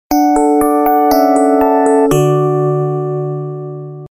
Kategorie SMS